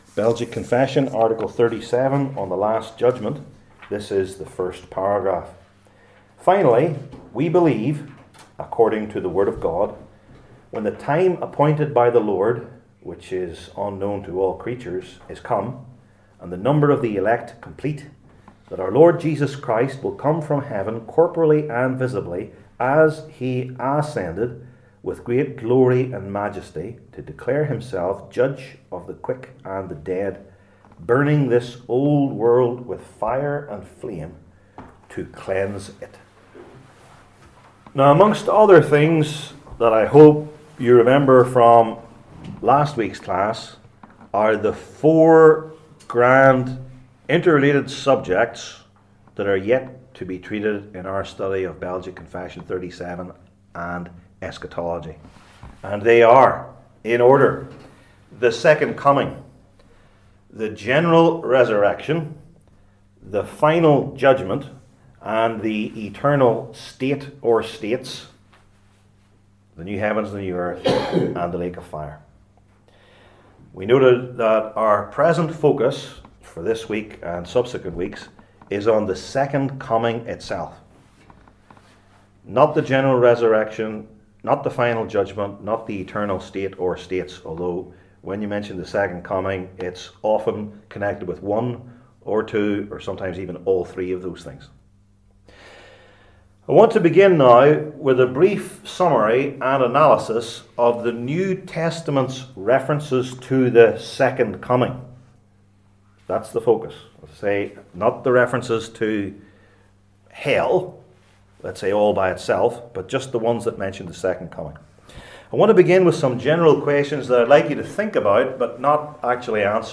Service Type: Belgic Confession Classes